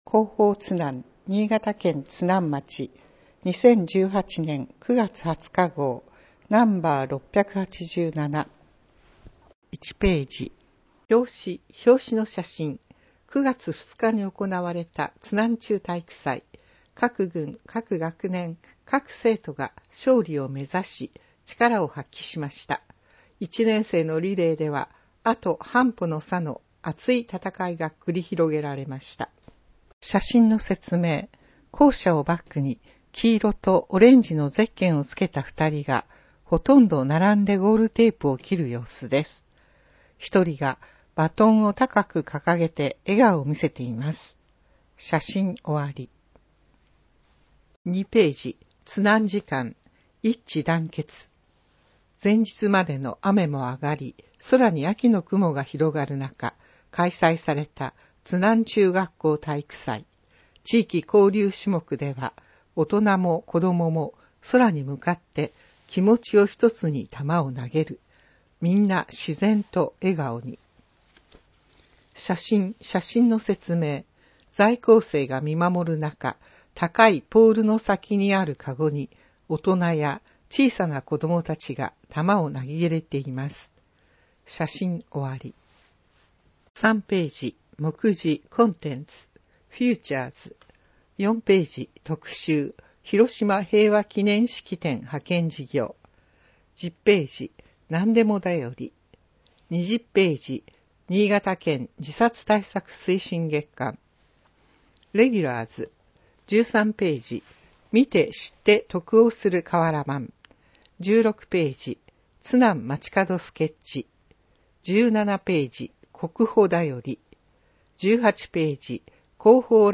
・広島平和記念式典派遣事業 ・なんでも便り ・新潟県自殺対策推進月間 広報つなん9月20日号 [PDFファイル／6.21MB] 広報つなん 平成30年9月20日号 音声版 再生時間 約1時間46分（音声再生用ソフトが起動します） 皆さまのご意見をお聞かせください お求めの情報が充分掲載されてましたでしょうか？